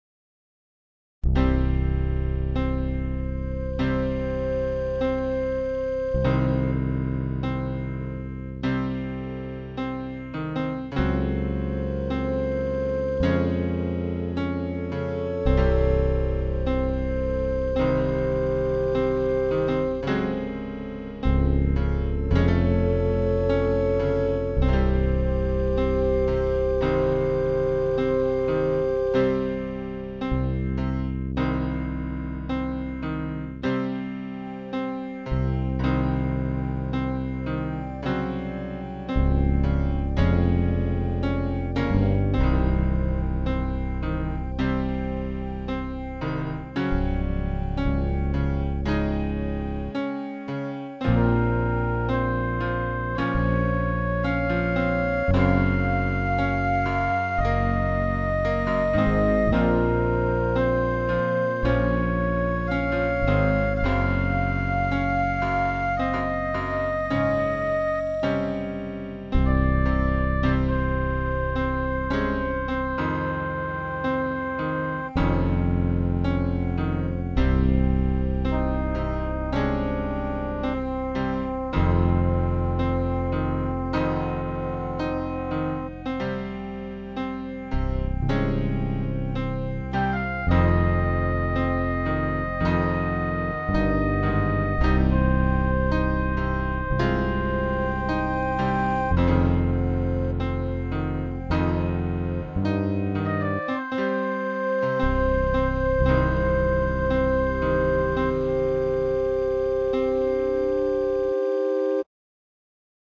Tune with Piano, Flute.